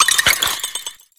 Audio / SE / Cries / VANILLISH.ogg